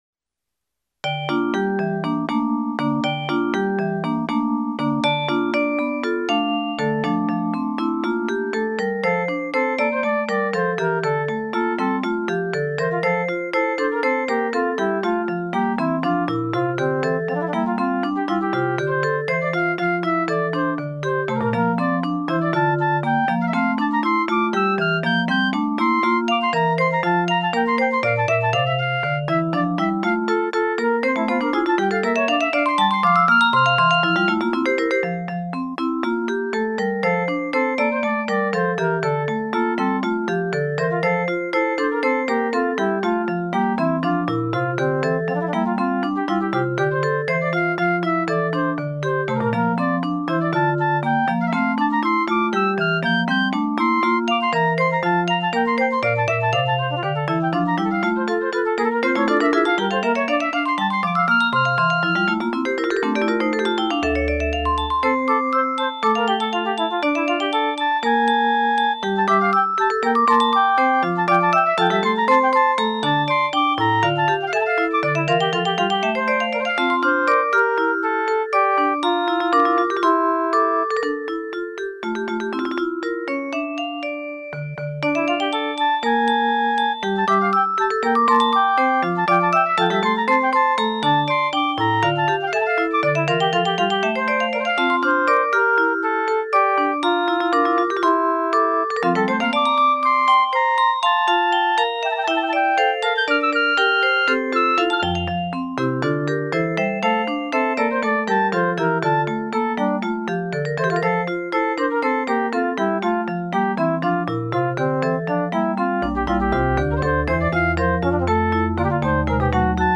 POLYPHONIC MUSIC